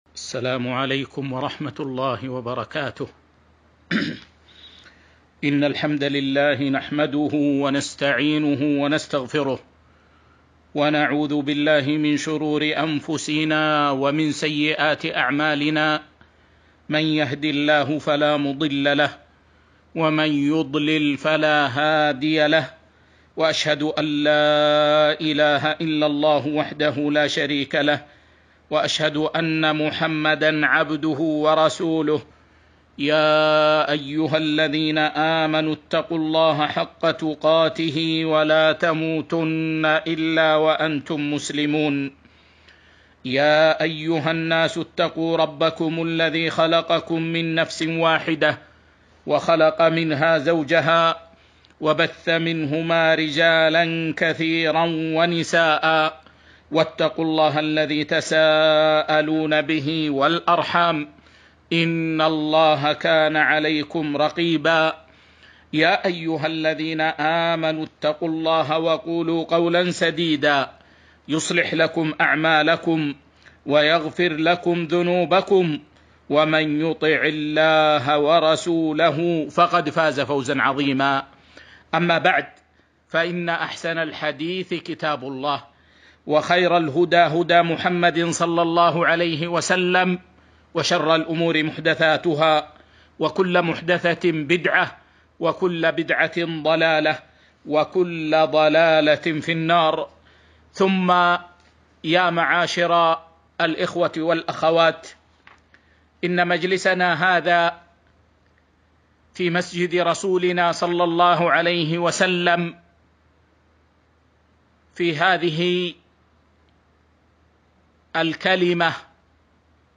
محاضرة - تفسير ﴿اليَومَ أَكمَلتُ لَكُم دينَكُم وَأَتمَمتُ عَلَيكُم نِعمَتي ...﴾